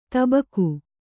♪ tabaku